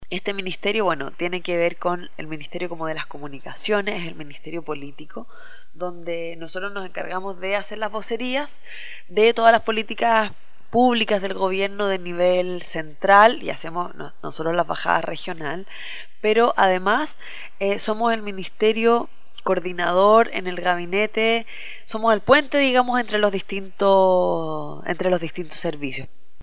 Proyecto “El mundo cívico que todos debemos conocer” entrevista a la Seremi de Gobierno Pía Bersezio